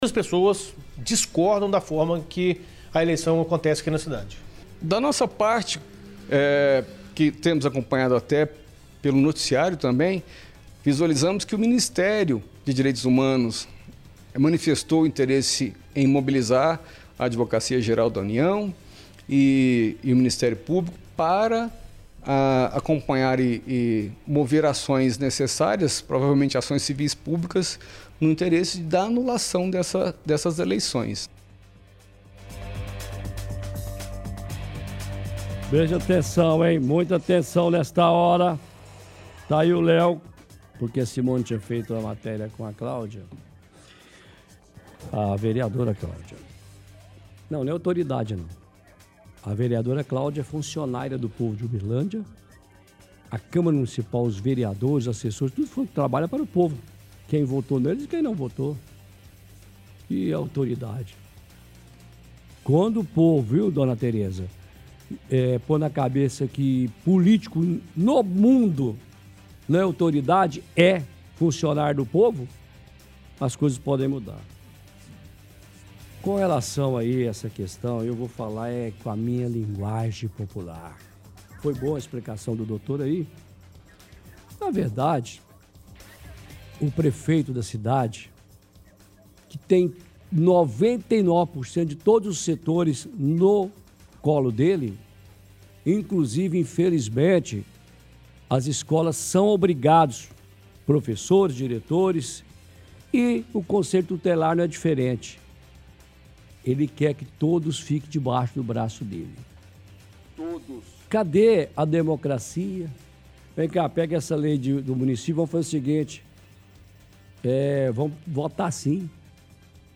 Comentário sobre eleição de conselheiros tutelares
– Toca áudio de parte da matéria sobre eleições para conselheiros tutelares.